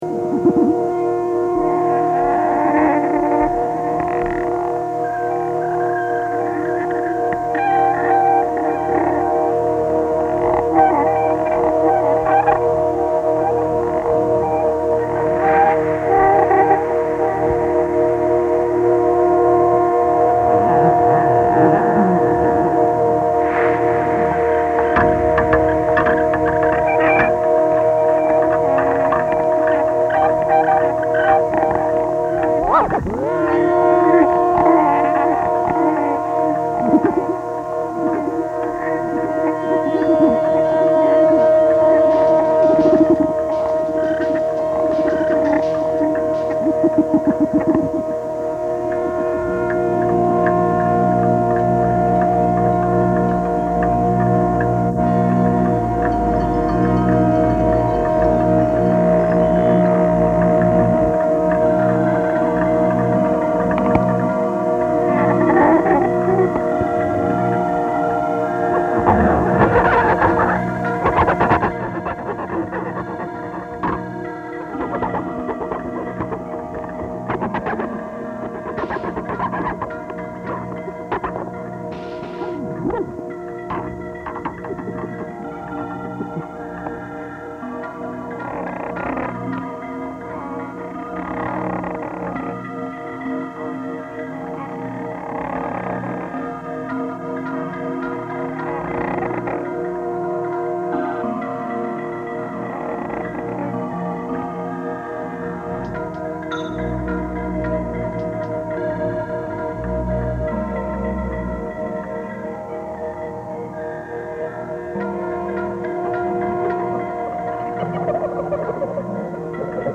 Live at Come the Freak On festival for Screw Music Forever in Brooklyn.